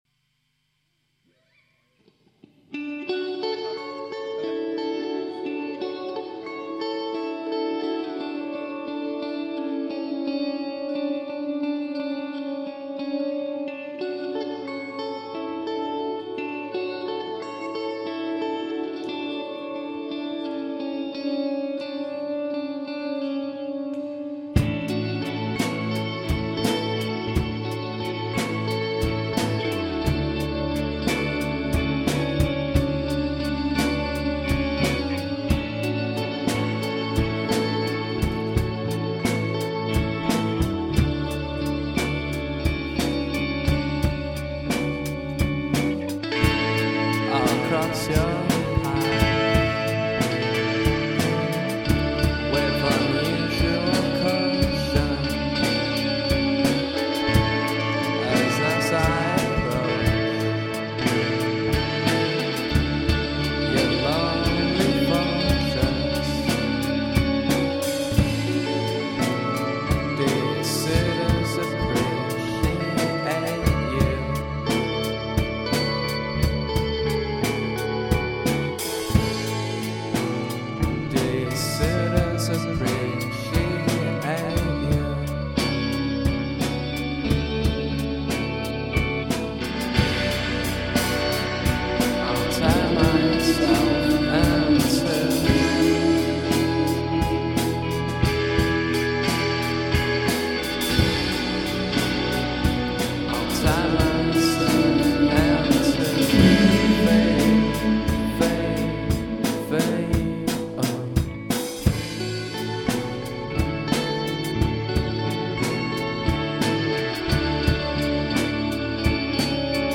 The skinny : Reverb overdose.